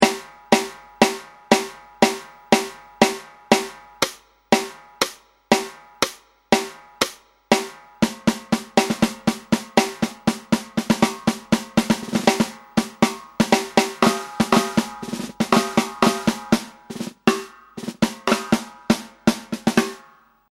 スネア（単音／リズム） ※金属音は、リムショットと言うスネアドラムの縁（ふち）を打つ音です。
Snare.mp3